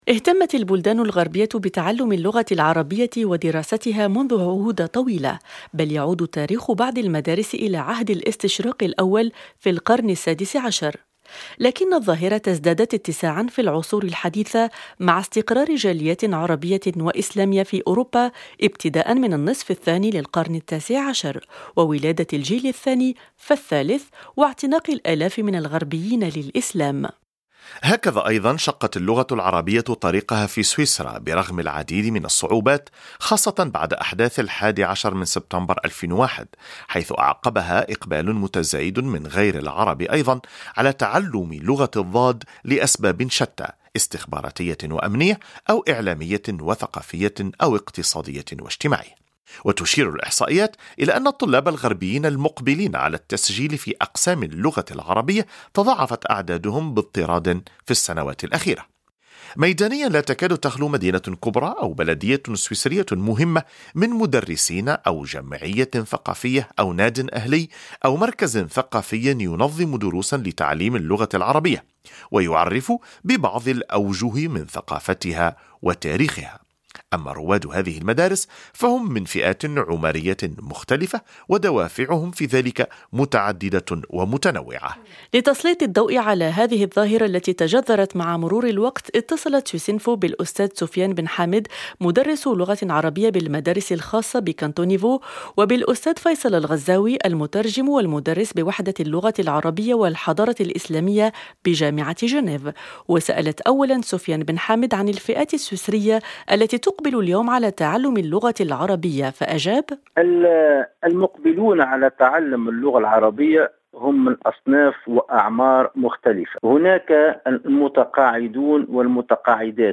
لمعرفة المزيد عن هذه الظاهرة، حاور